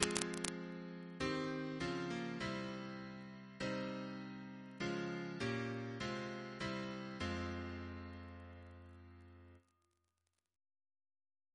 Single chant in F minor Composer: Thomas Purcell (d.1682) Reference psalters: H1982: S255 S268; PP/SNCB: 220